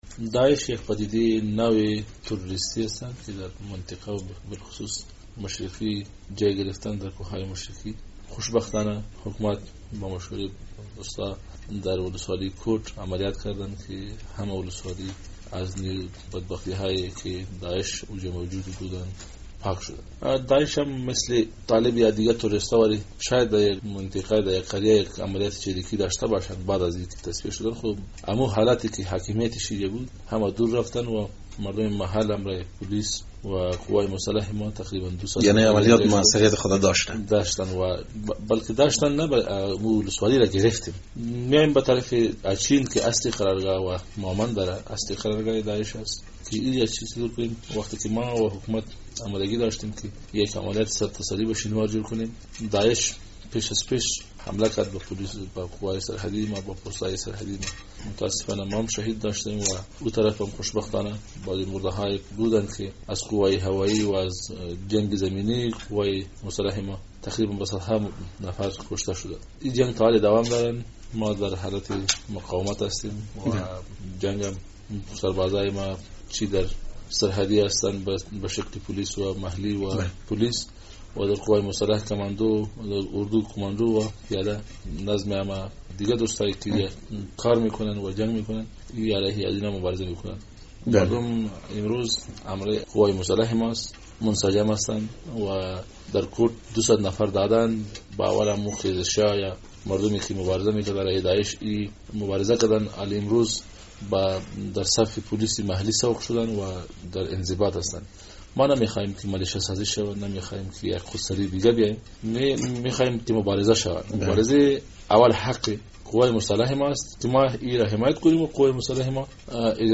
مصاحبه - صدا
فضل هادی مسلمیار در مصاحبه اختصاصی با رادیو آزادی گفت، افراد این گروه که هم اکنون در ننگرهار بر ضد نیروهای افغان می جنگند، از لحاظ تاکتیک جنگ بالاتر از یک گروهء تروریستی، آموزش دیده اند.